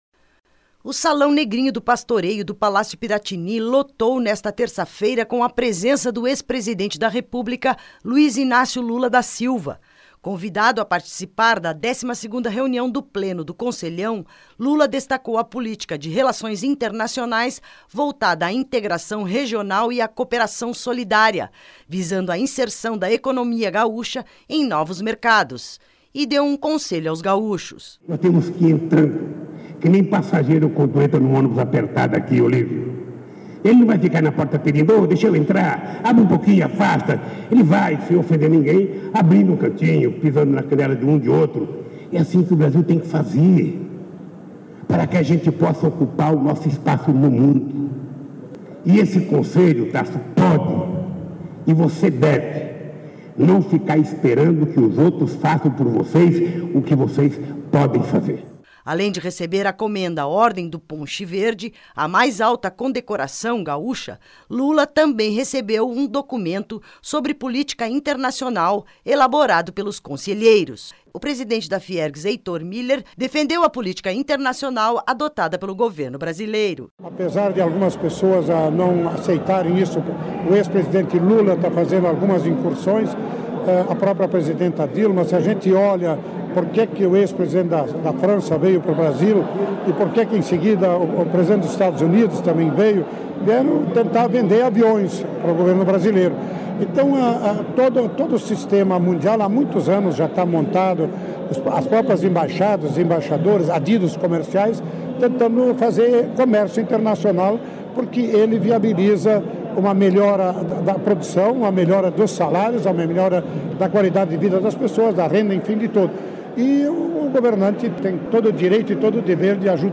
O Salão Negrinho do Pastoreio do Palácio Piratini lotou nesta terça-feira (14) com a presença do ex-presidente da República Luiz Inácio Lula da Silva.